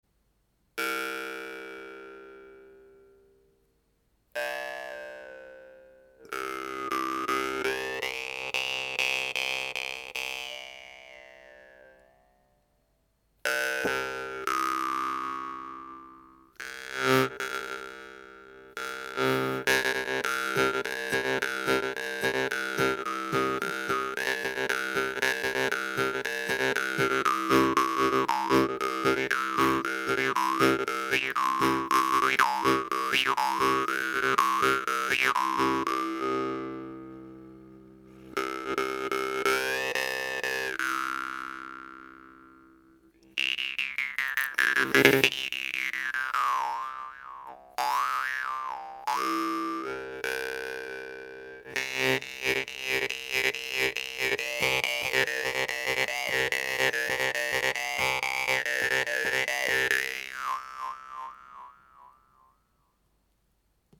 Les tonalités de ces guimbardes se situe en majorité dans l’octave 1 et offrent donc des sons assez bas et profond, d’où un sustain très appréciable. Idéale pour un jeu méditatif mais également pour monter dans les tours, le jeu avec le souffle lui va parfaitement!!